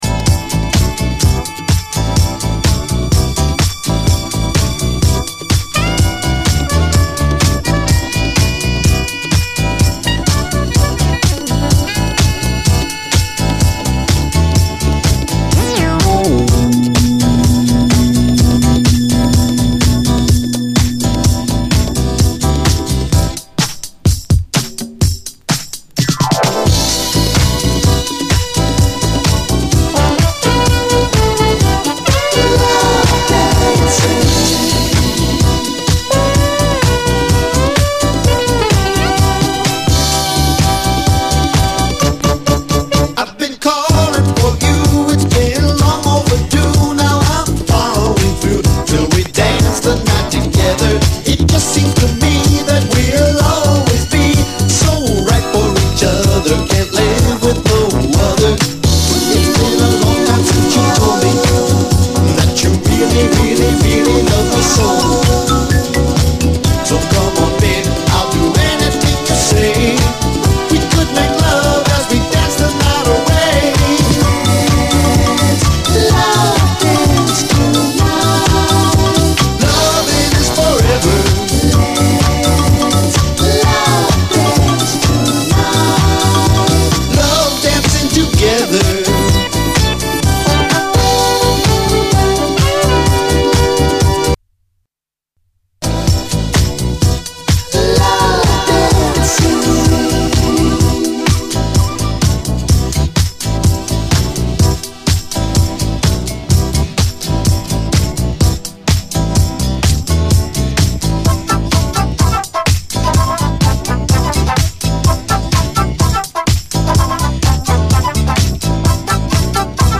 しなやかで妖しいシンセのメロディーが最高です。